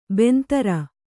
♪ bentara